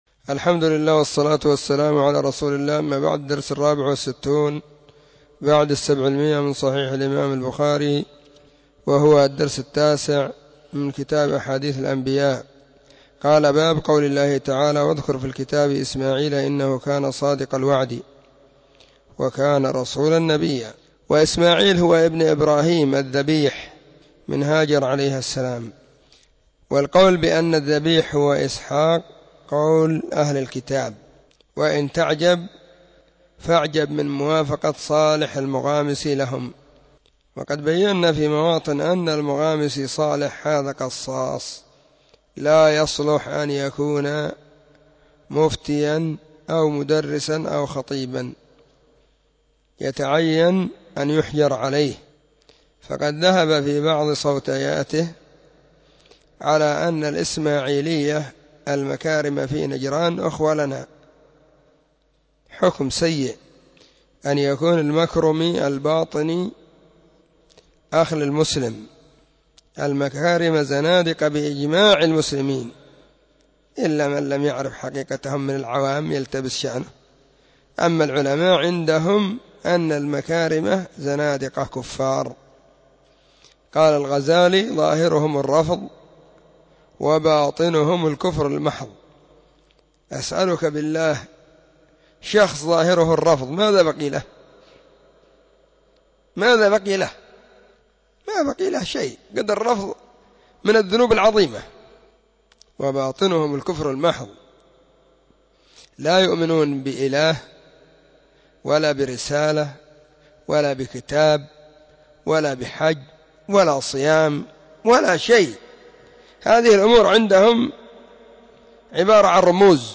🕐 [بين مغرب وعشاء – الدرس الثاني]
كتاب-أحاديث-الأنبياء-الدرس-9.mp3